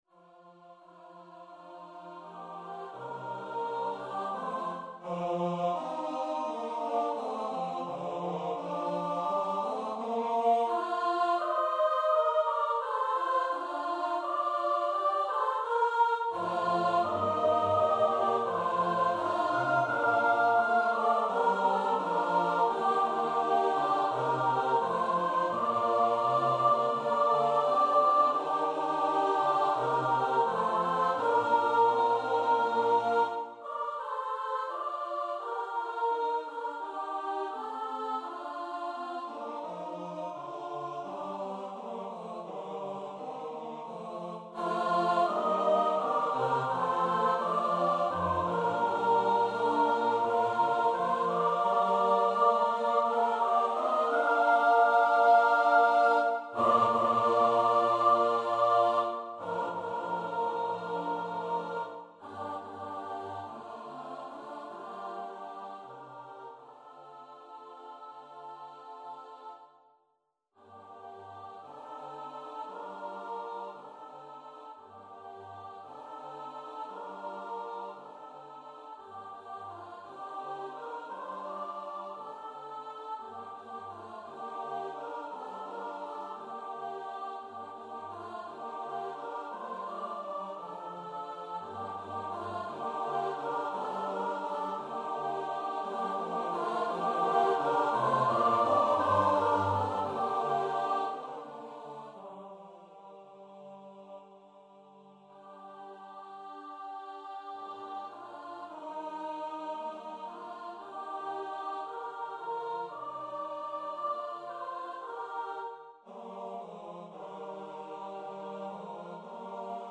a cappella SATB choir